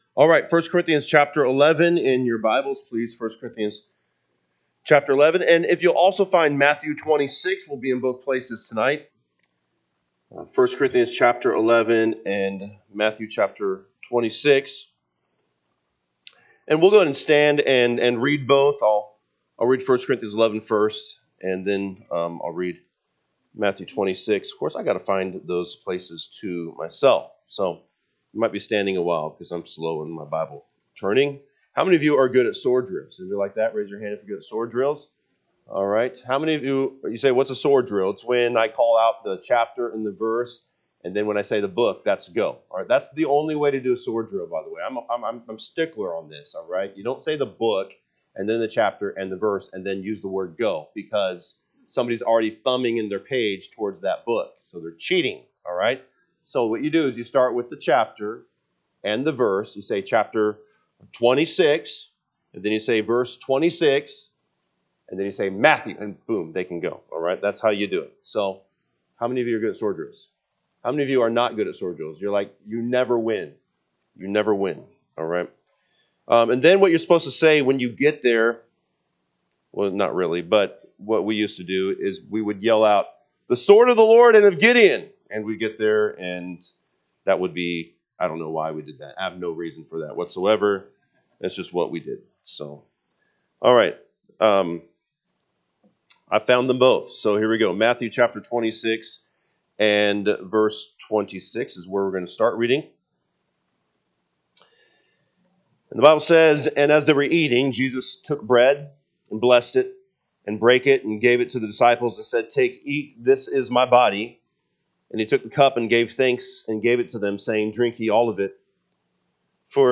Sunday PM Message